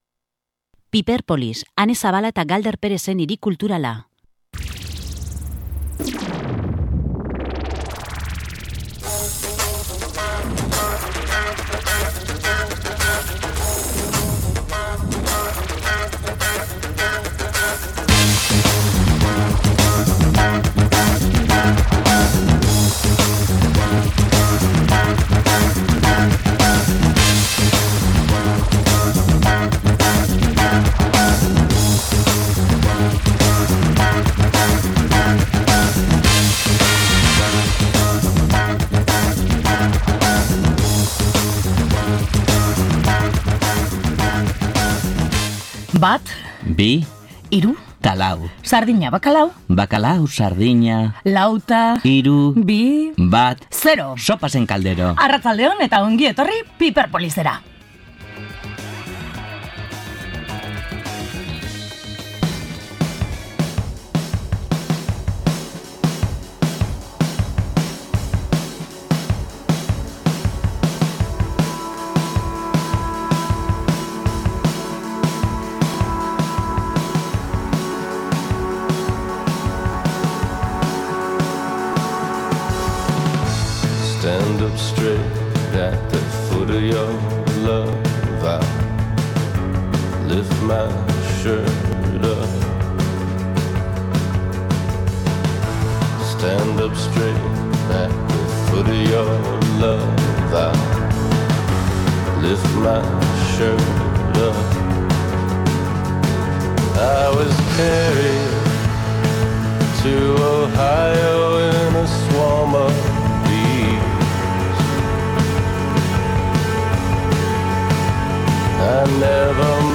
Izugarri gozatzeaz gain, grabagailua eraman eta zenbait ahots jaso genituen zuentzat.